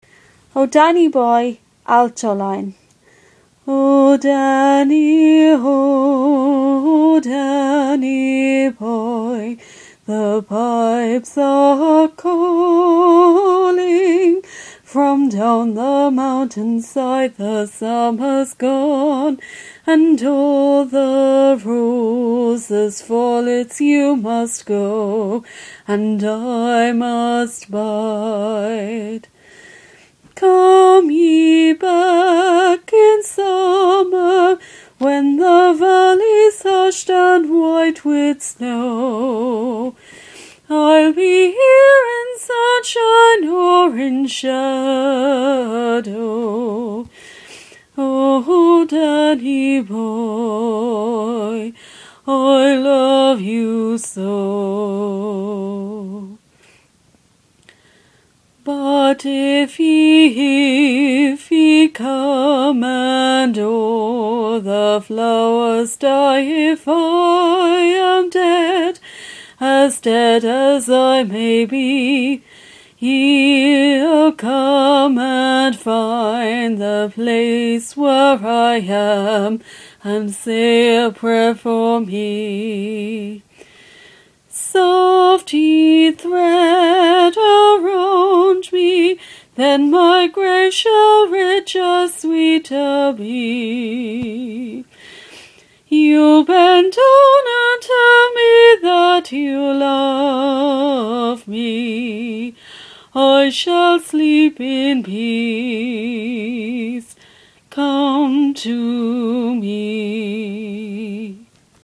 Simple Harmonies
Harmony
DannyBoy-alto-.mp3